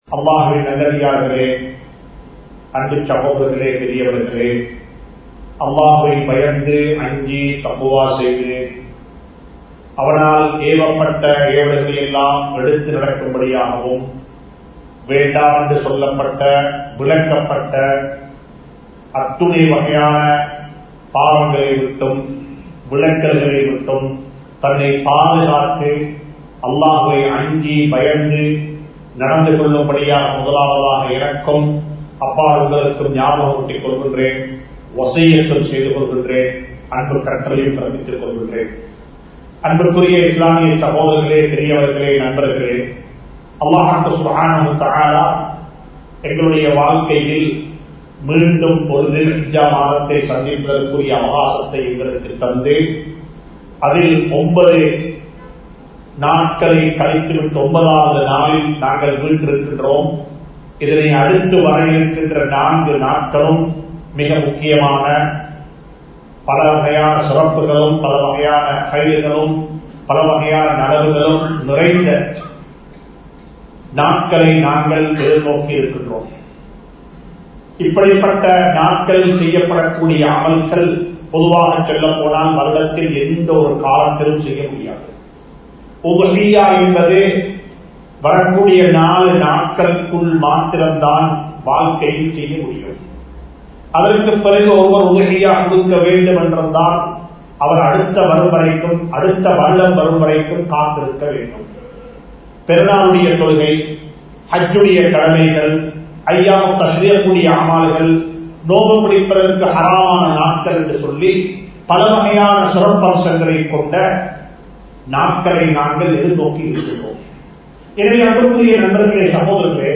எங்களை நாங்களே மாற்றுவோம் (We will Change Ourselves) | Audio Bayans | All Ceylon Muslim Youth Community | Addalaichenai